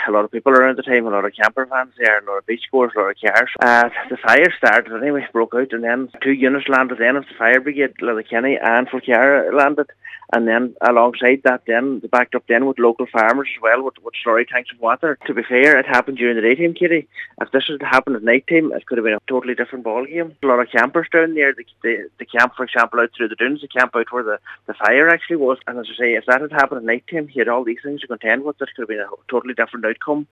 Cllr Michael McClafferty says if the fire had happened during the night, it could have been a different story….
Thanking them today, Glenties MD Cathaoirleach said had this happened at night, it could have led to loss of life and property, adding that the fire service inn Donegal is stretched to the limit.